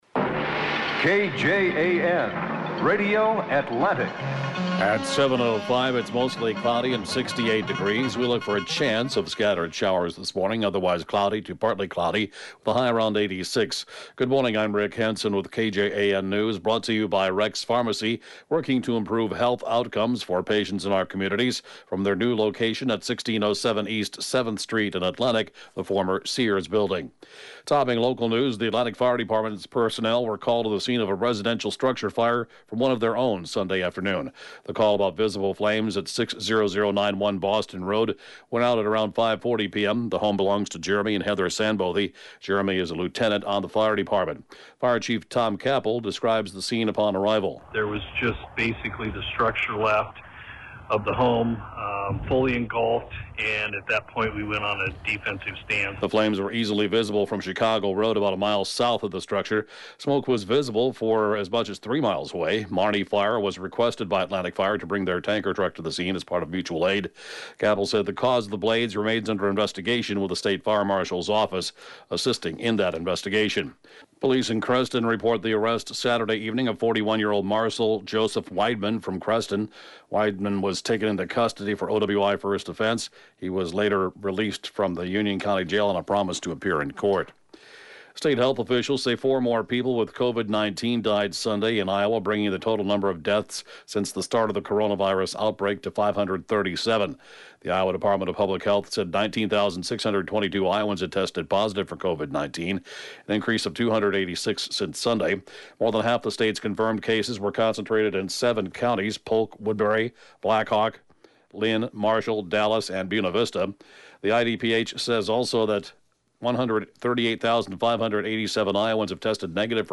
(Podcast) KJAN Morning News & Funeral report, 6/1/20